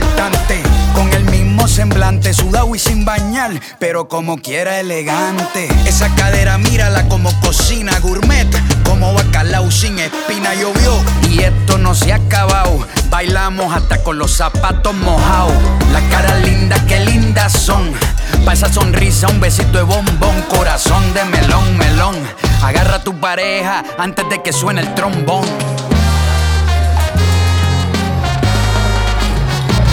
Genre: Pop Latino